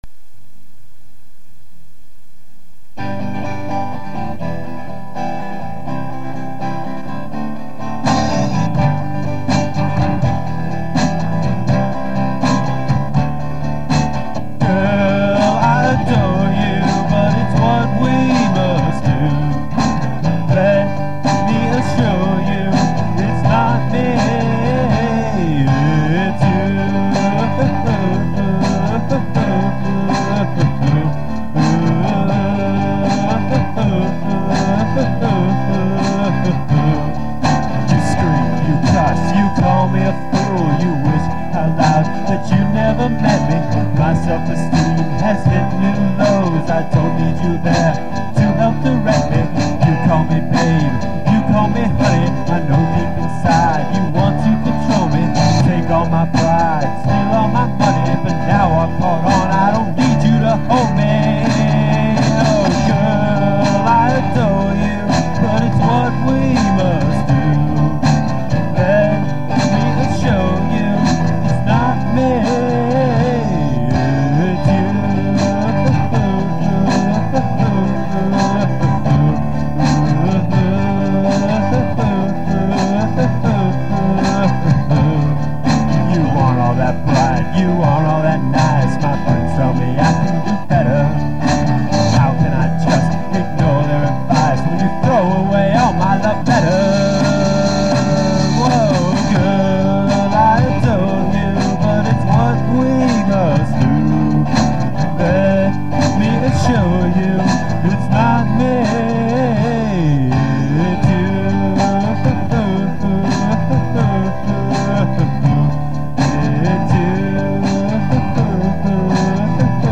From a St. Louis basement comes a lo-fi gem.